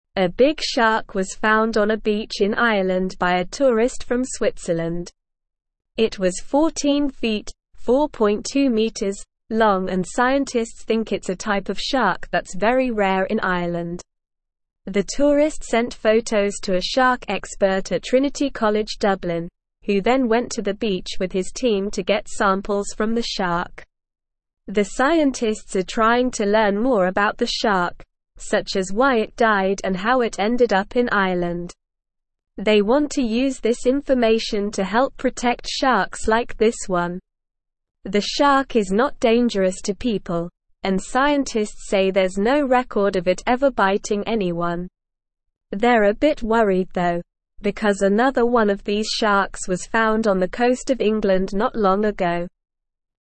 Slow
English-Newsroom-Beginner-SLOW-Reading-Big-Shark-Found-on-Irish-Beach.mp3